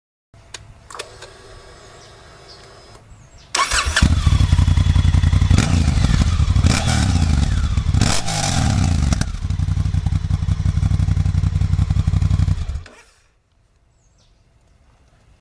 Hi zusammen, weiß jemand, ob es irgendwo einen Klingelton gibt mit dem Sound von unserer Africa Twin ?
Honda CRF 1000 L Africa Twin.mp4 Ein Fremder ist einfach ein Freund den du noch nicht getroffen hast.